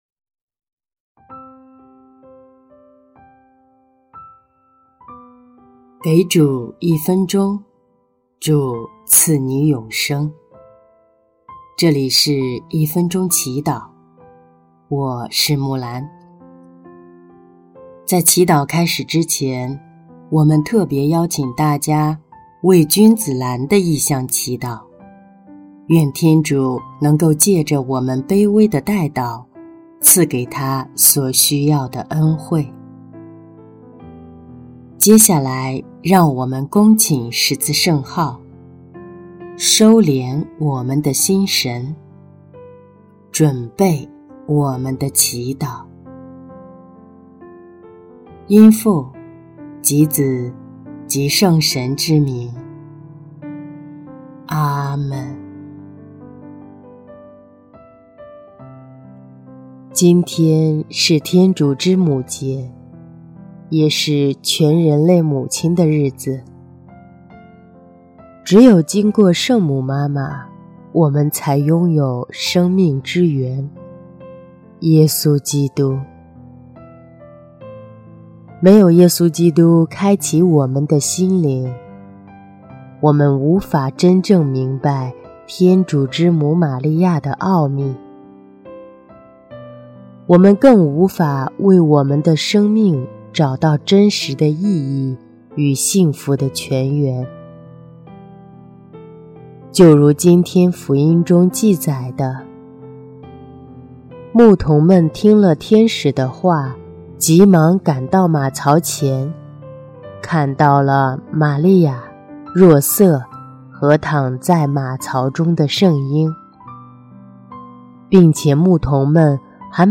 音乐：第二届华语圣歌大赛优秀奖歌曲《圣母，为我祈！》